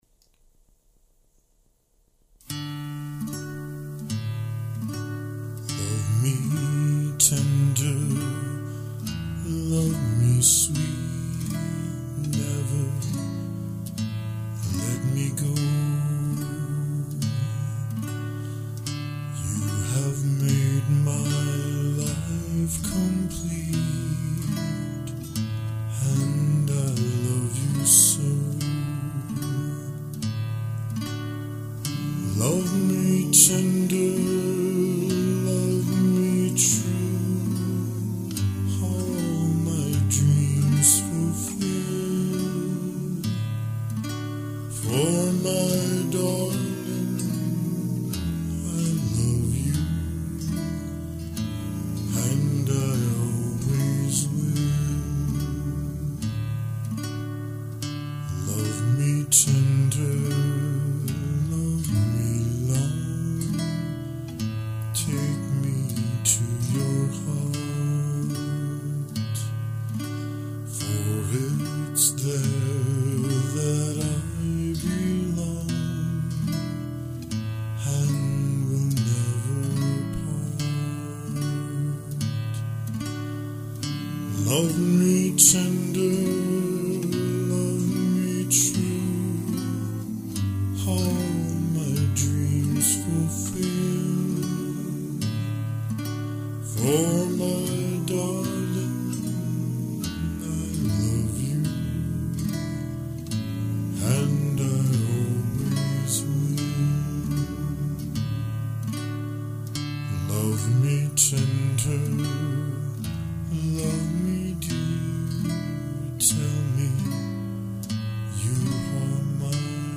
Drum machine